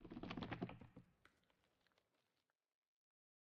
pale_hanging_moss2.ogg